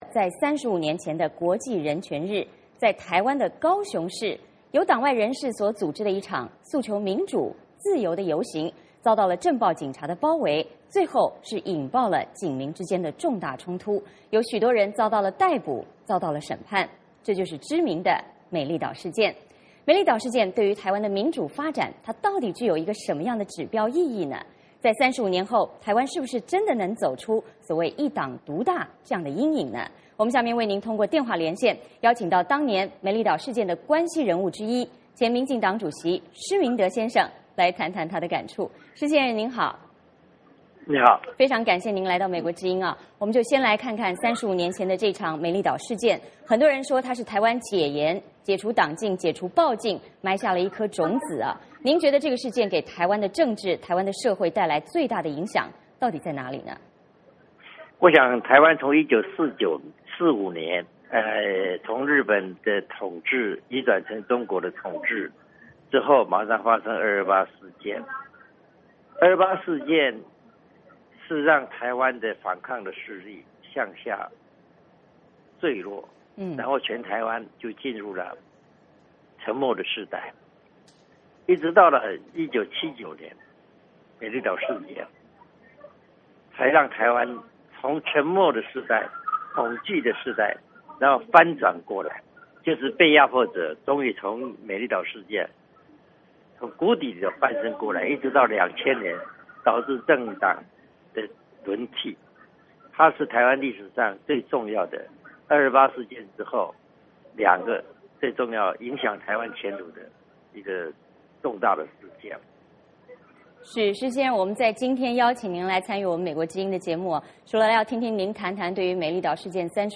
美丽岛事件对台湾的民主发展具有什么样的指标意义?35年后的台湾是否真的走出一党独大的阴影？我们通过电话连线请当年美丽岛事件的关系人物之一、前民进党主席施明德先生来谈谈他的感触。